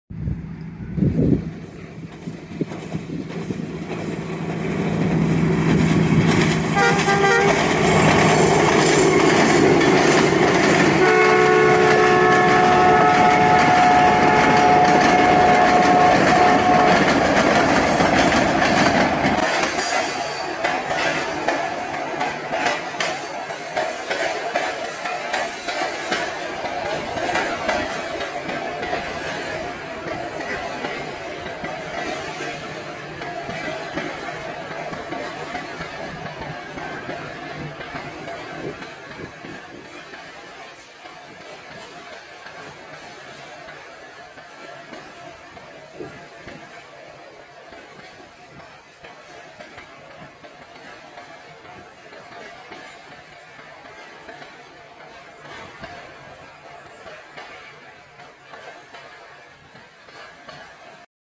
Arrival of Fareed express 37up#tracksound